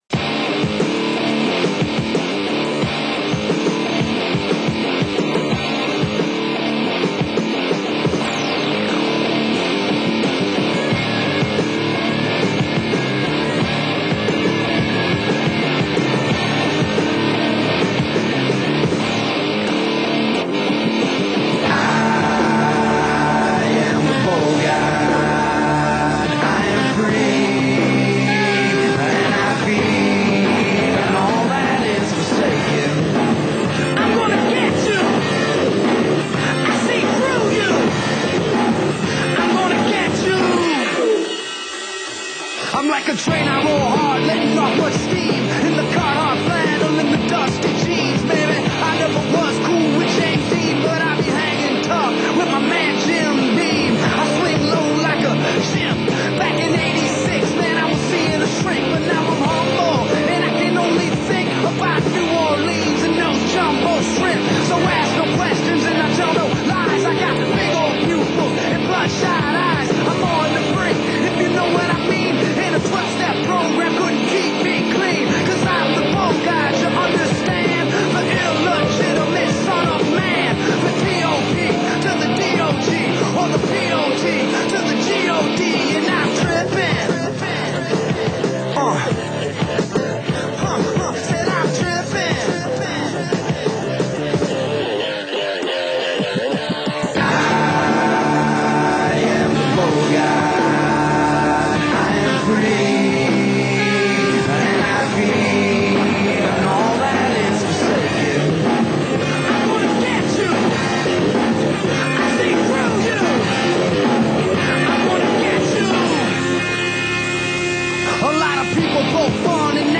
House of XI's Heavy Metal Horror Video Music Featuring: